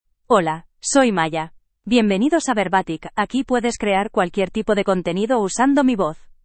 Maya — Female Spanish (Spain) AI Voice | TTS, Voice Cloning & Video | Verbatik AI
Maya is a female AI voice for Spanish (Spain).
Voice sample
Listen to Maya's female Spanish voice.
Female
Maya delivers clear pronunciation with authentic Spain Spanish intonation, making your content sound professionally produced.